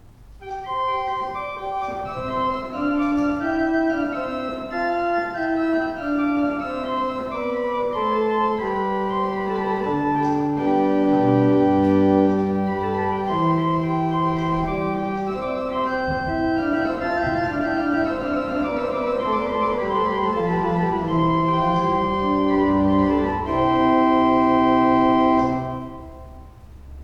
Listen to an improvisation on the Principal dulcis 8' and Principal 4' by clicking
Principal_Dulcis_8_Principal_4.wav